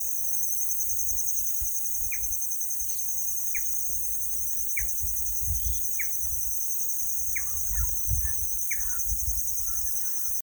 Ash-throated Crake (Mustelirallus albicollis)
Province / Department: Corrientes
Location or protected area: Concepción del Yaguareté Corá
Condition: Wild
Certainty: Observed, Recorded vocal